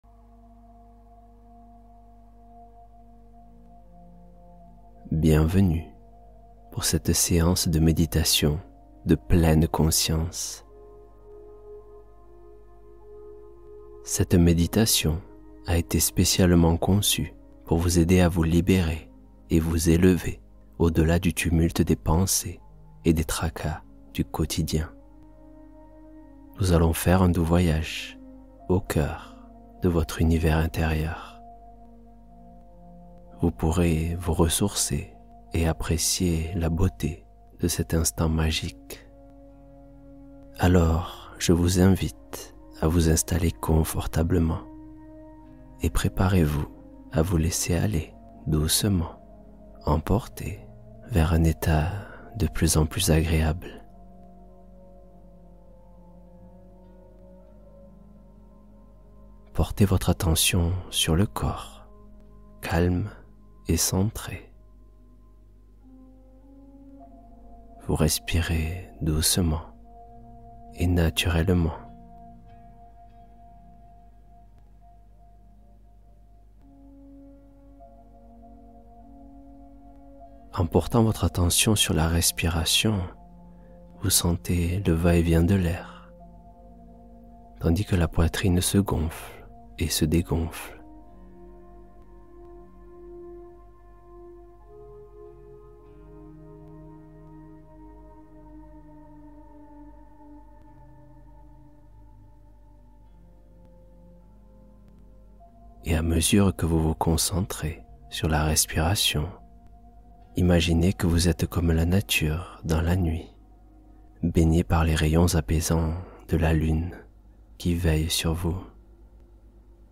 Libérez Votre Esprit de Toutes Pensées | Méditation de Pleine Conscience Avec Histoire Fascinante